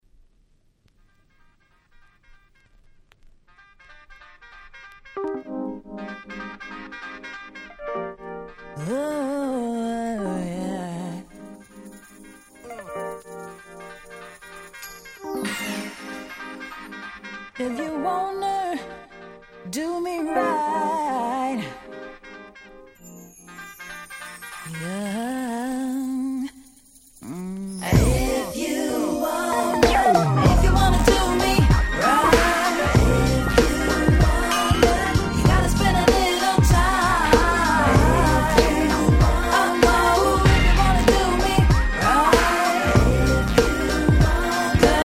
UK R&Bや90's R&Bが好きならど真ん中なはず！！